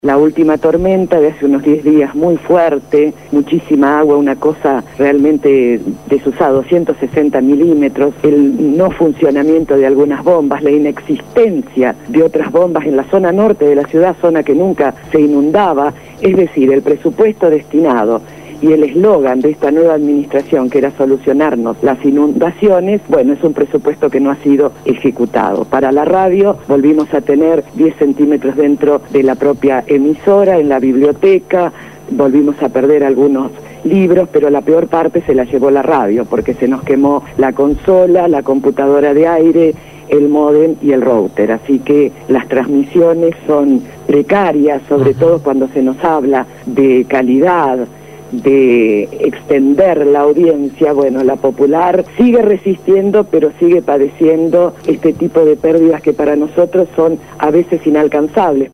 habló esta mañana en el programa Punto de Partida de Radio Gráfica FM 89.3 sobre el panorama de la ciudad de Santa Fe luego de la tormenta de los últimos días.